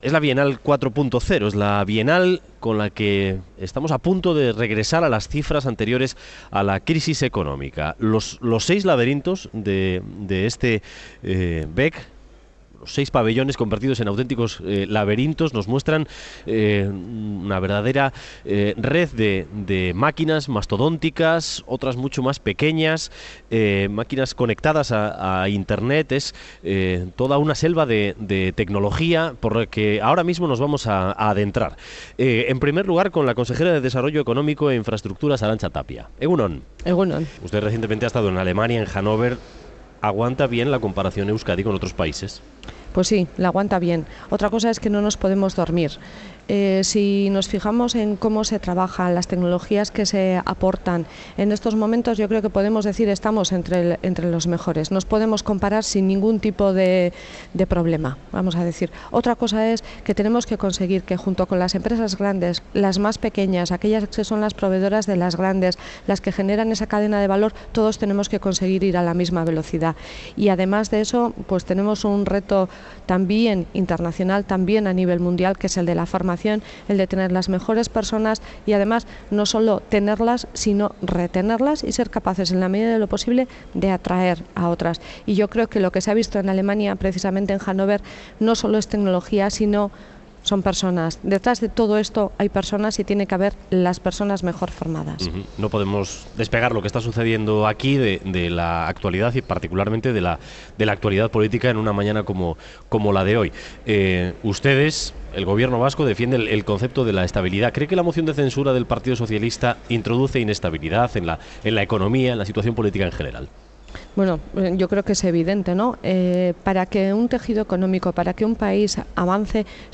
Audio: La consejera de Desarrollo Económico del Gobierno Vasco cree que la moción de censura genera incertidumbre económica. Son declaraciones antes de la inauguración de la Bienal de Máquina Herramienta en el BEC de Barakaldo